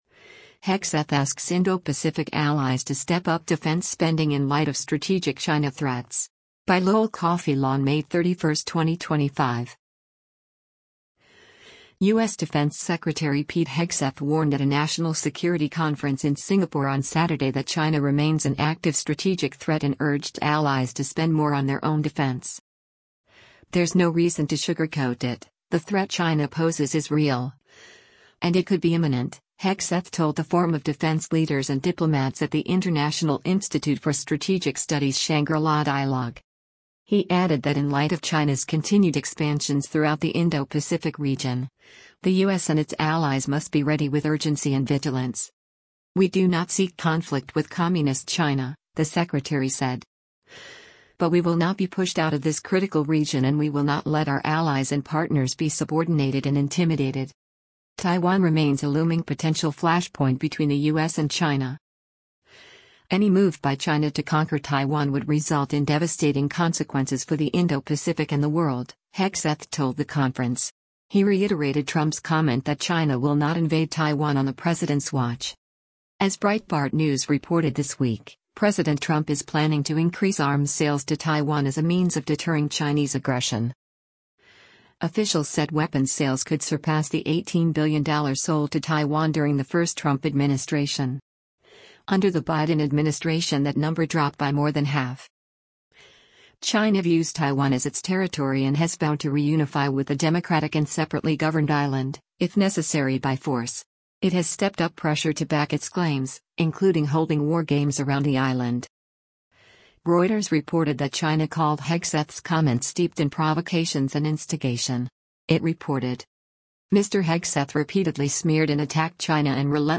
Pete Hegseth, US secretary of defense, speaks during the IISS Shangri-La Dialogue in Singa